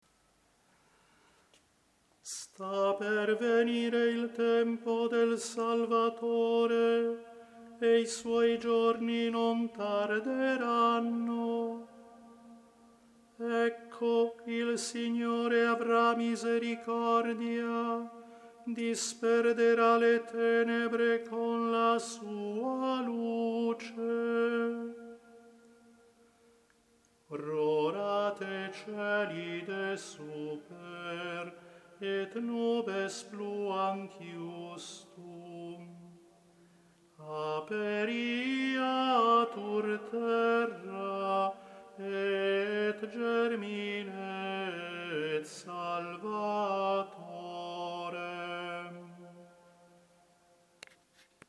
Si propone un tono salmodico ad hoc per ogni domenica di Avvento, che possa ben adattarsi alla semplice e magnifica antifona ambrosiana Rorate Coeli.